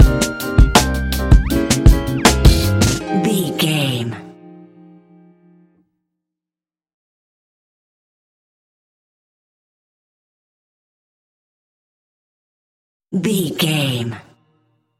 Ionian/Major
D♯
chilled
laid back
Lounge
sparse
new age
chilled electronica
ambient
atmospheric